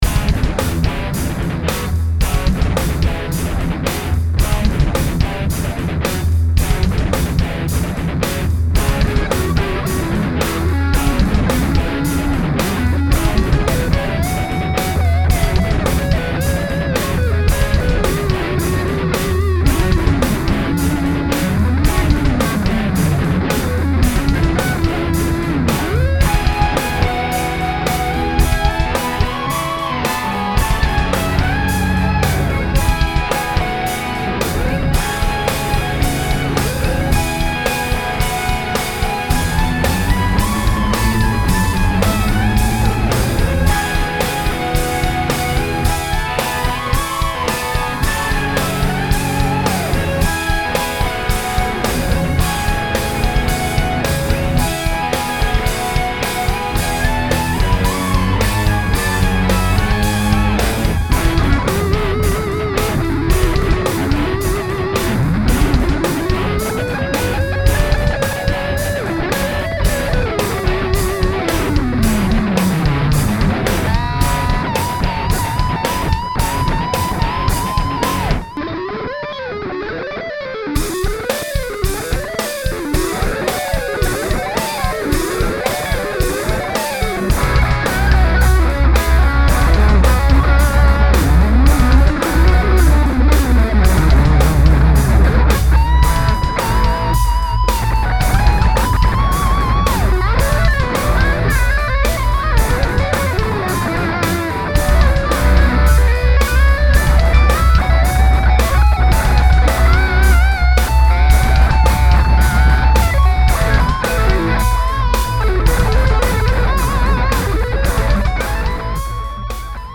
latest and probably heaviest thing I've recorded sofar
the "scratching" during the end solo is a guitar and not a turntable...